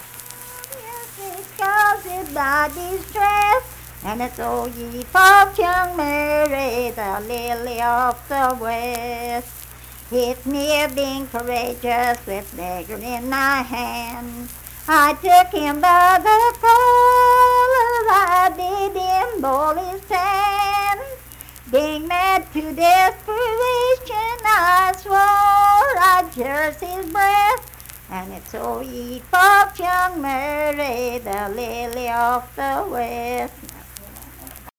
Unaccompanied vocal music performance
Verse-refrain 1(6).
Voice (sung)